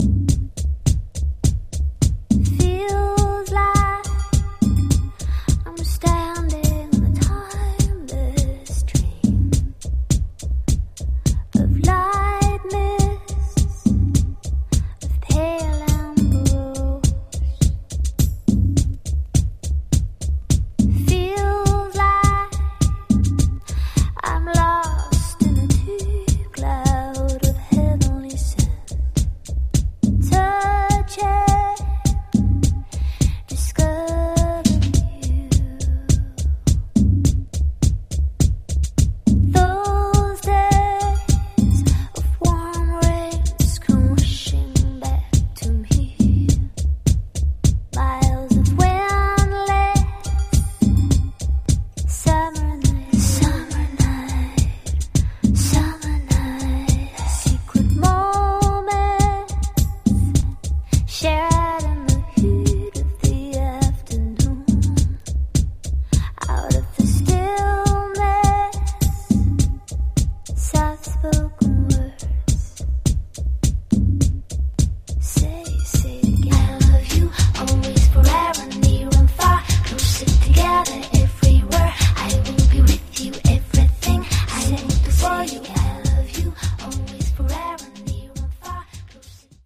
104 bpm
Clean Version